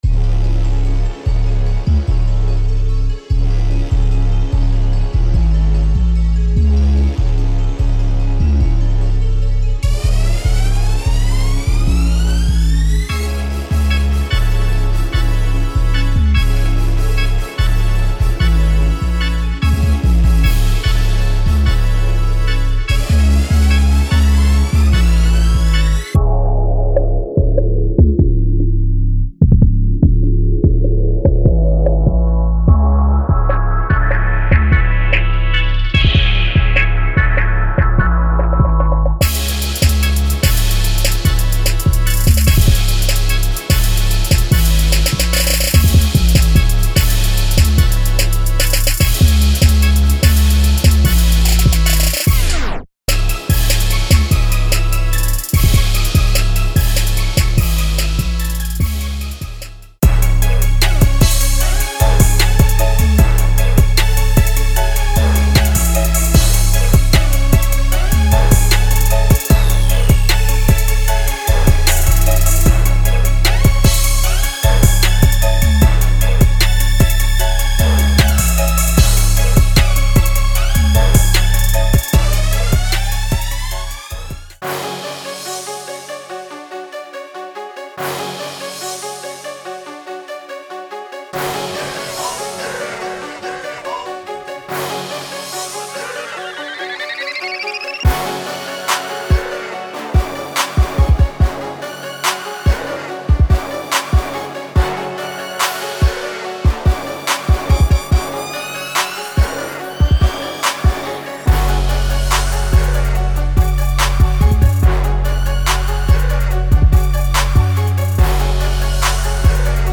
Dirty South Loops Set
Kit includes hard hittin 808s, blazin brass, crazy arps, catchy synths, and hot leads.